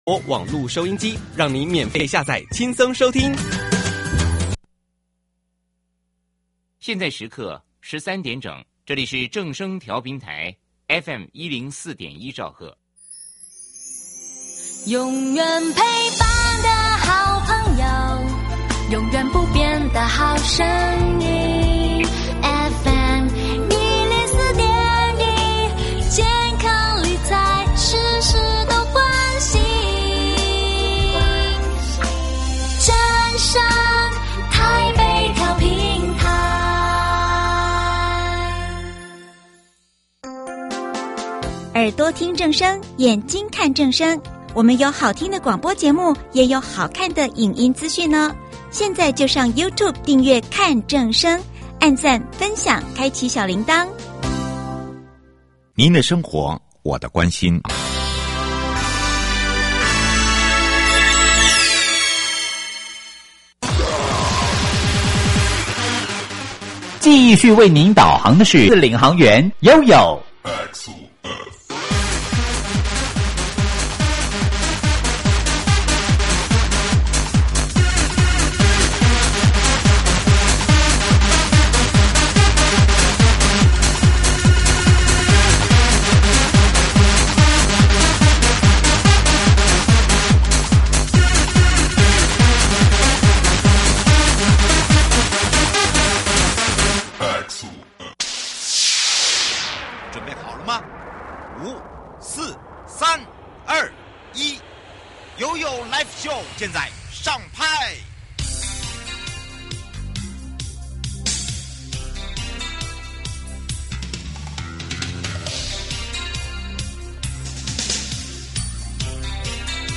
今天的來賓是基隆市政府工務處處長，讓我們一起深入了解這些工程如何真正讓孩子、家長、長者和居民『有感』，以及基隆未來的步行願景。」